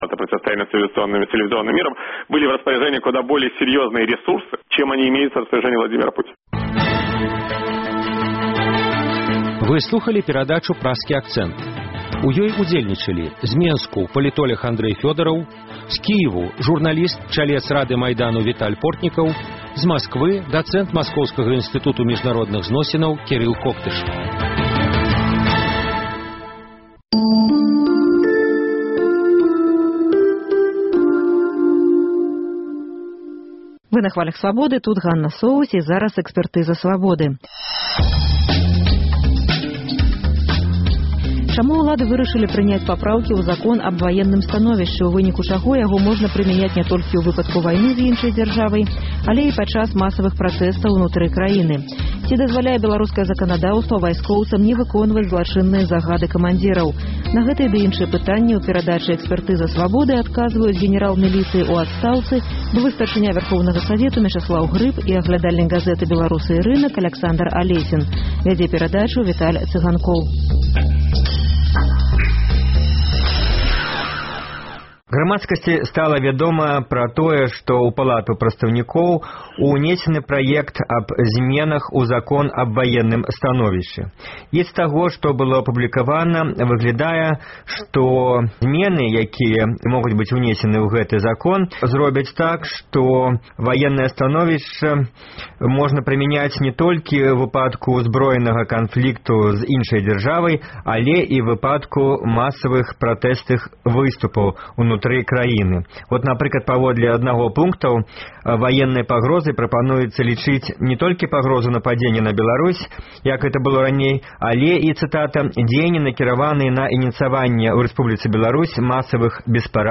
Чаму ўлады вырашылі прыняць папраўкі ў закон «Аб ваенным становішчы», у выніку чаго яго можна прымяняць ня толькі ў выпадку вайны зь іншай дзяржавай, але і падчас масавых пратэстаў унутры краіны? Ці дазваляе беларускае заканадаўства вайскоўцам не выконваць злачынныя загады камандзіраў? Удзельнічаюць генэрал міліцыі ў адстаўцы, былы Старшыня Вярхоўнага Савету Мечыслаў Грыб